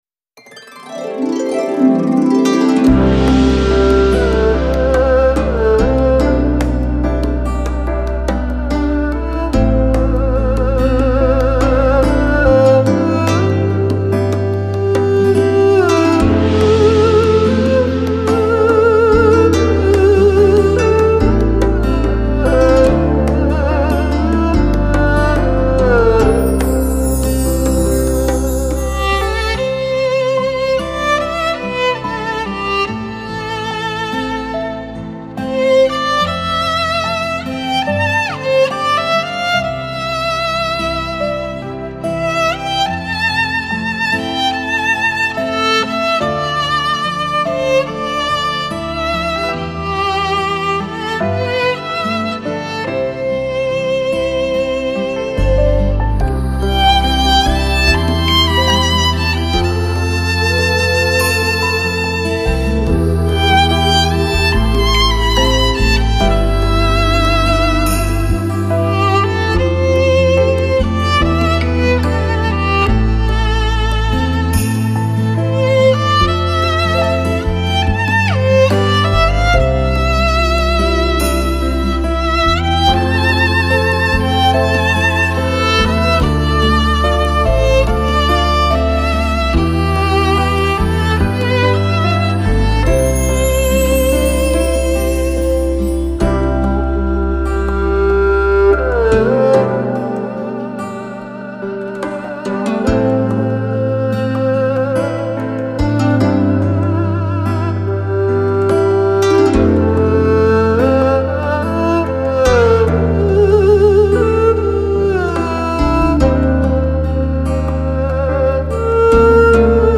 新世纪音乐
小提琴
柔情小提琴的万种风情
西乐与中乐遇见之美
传统与现代触碰之醉